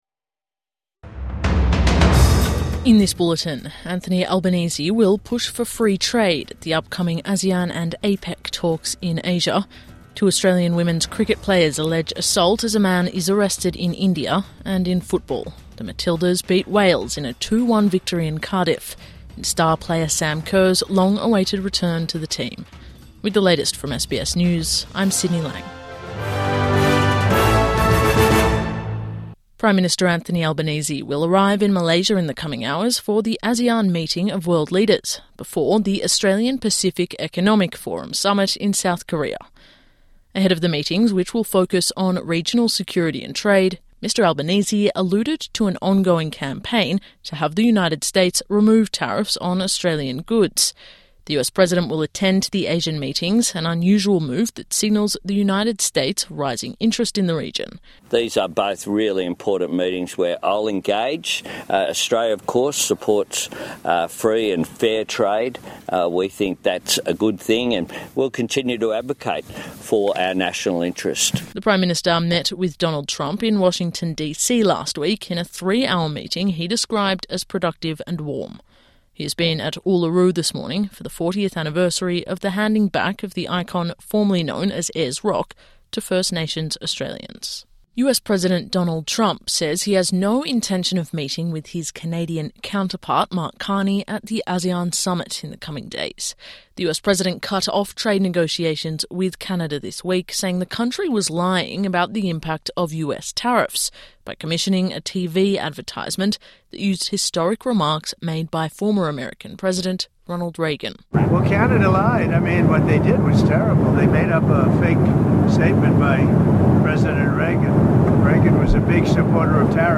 Midday News Bulletin 26 October 25